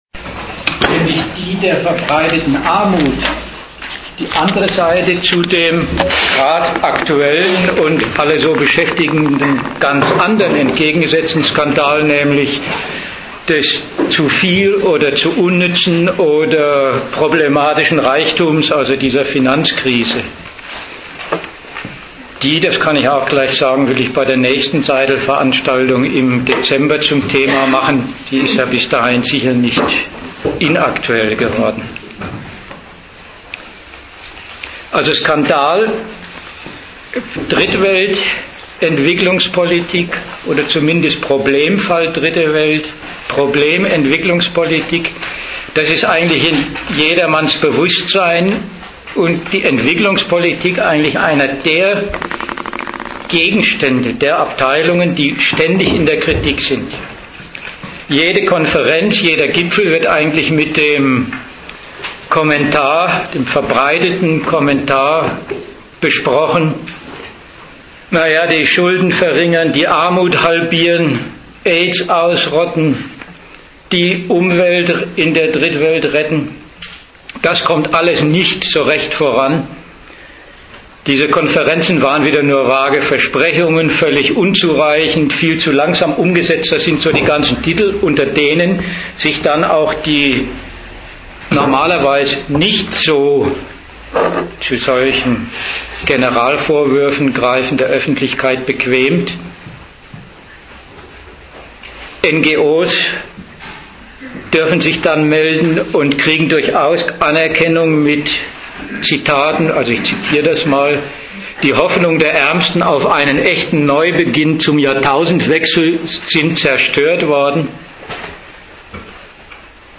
Ort München
Dozent Gastreferenten der Zeitschrift GegenStandpunkt Die ‚Entwicklungsländer’: eine fertige Welt von ‚failing states’ im globalisierten Kapitalismus